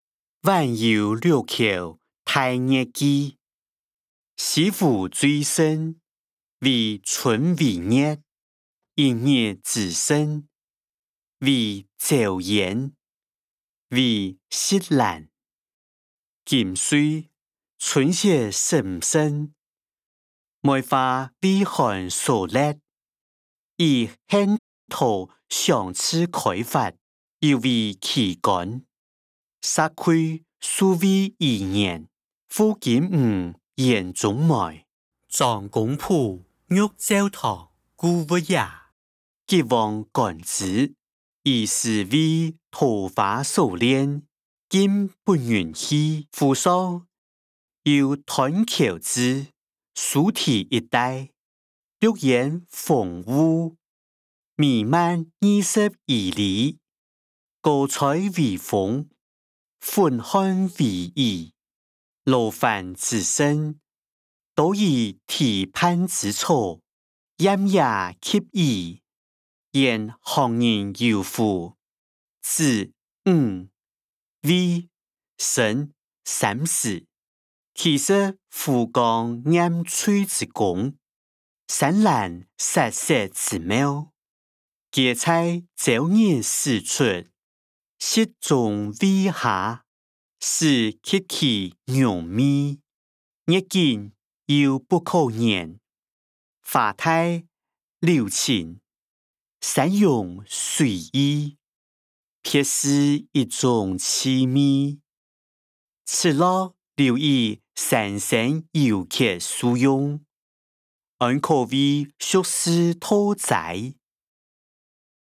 歷代散文-晚遊六橋待月記音檔(四縣腔)